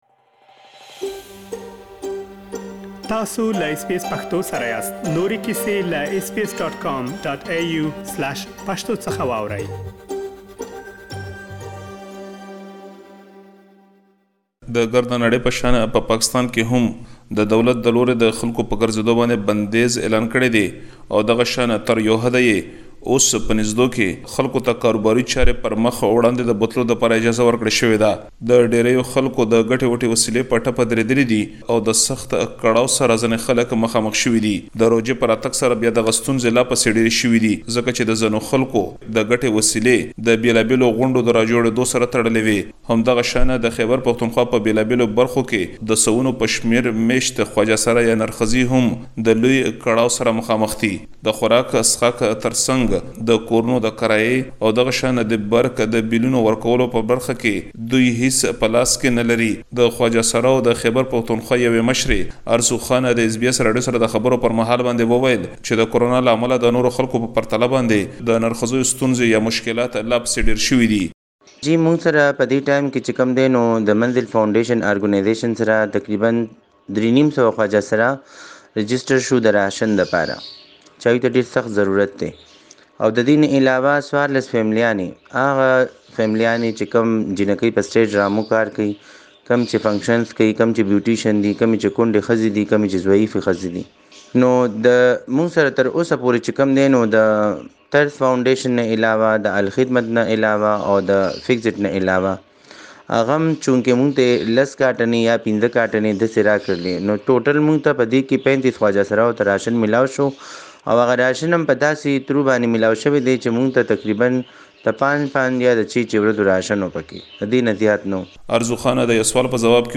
له ځينو نر ښځو سره خبرې کړي.